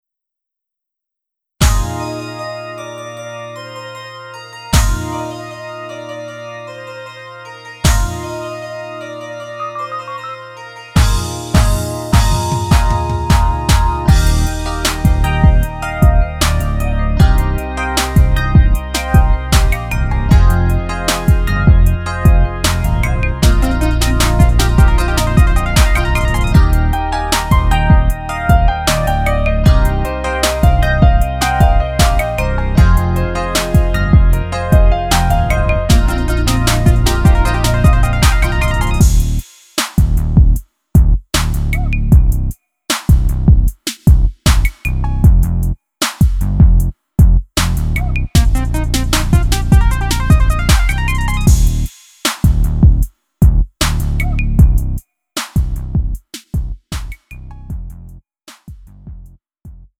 음정 -1키 3:25
장르 구분 Lite MR